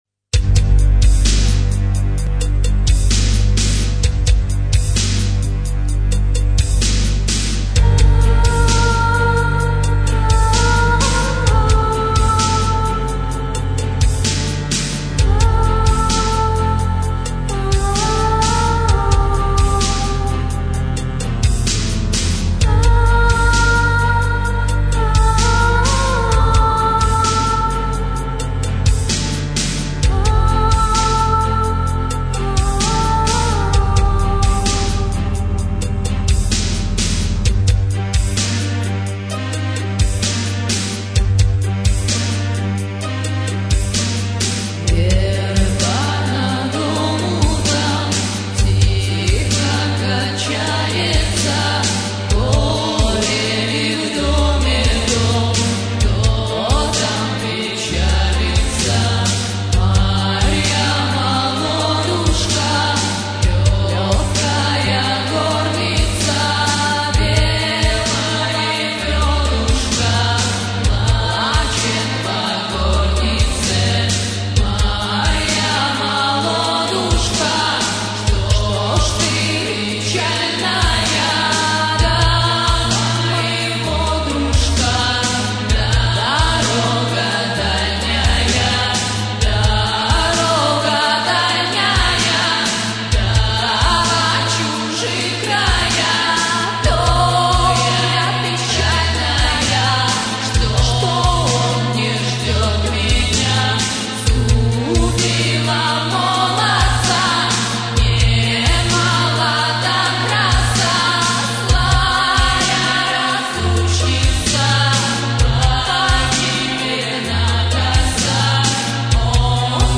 Демоальбом - г. Талдом